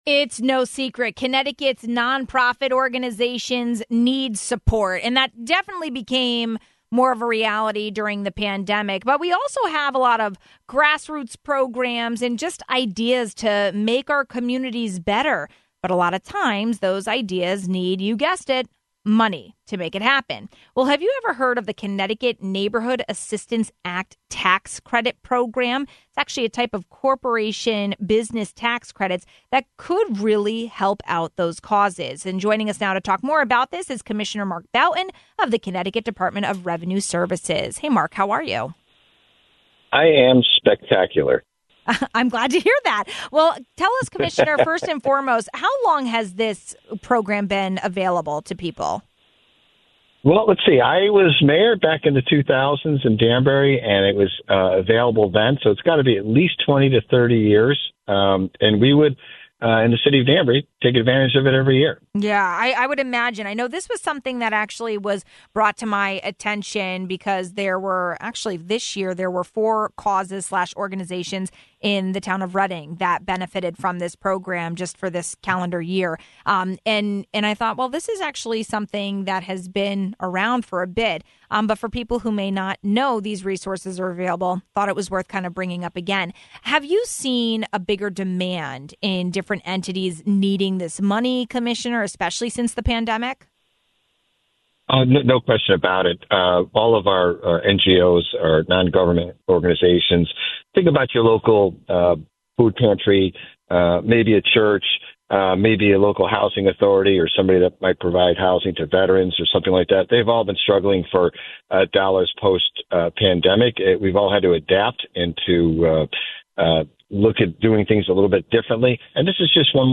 Have you ever heard of the Connecticut Neighborhood Assistance Act Tax Credit Program? It’s a type of corporation business tax credit that can really help! Commissioner Mark Boughton of the Connecticut Department of Revenue Services explains.